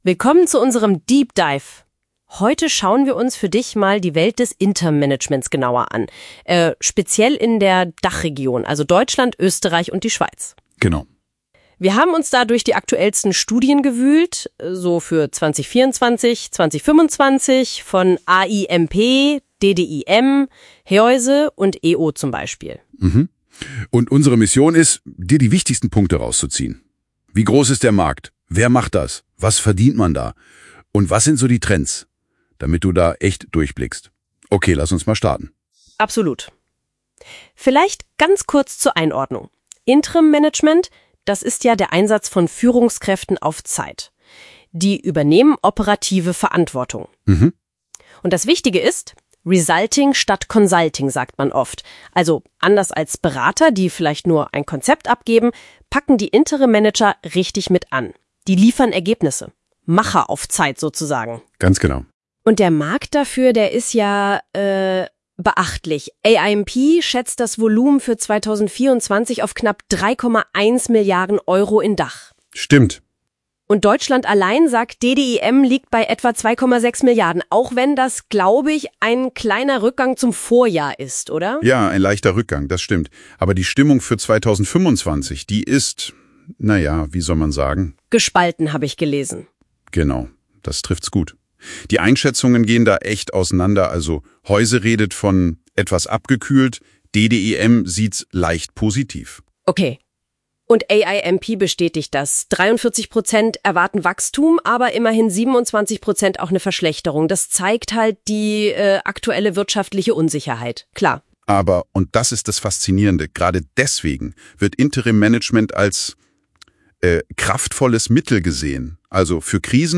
Der Podcast wurde von künstlicher Intelligenz generiert.